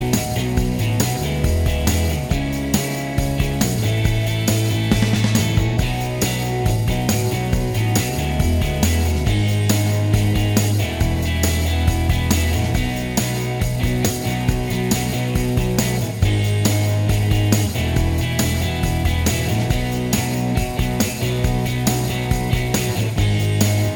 Minus Lead And Solo Indie / Alternative 5:14 Buy £1.50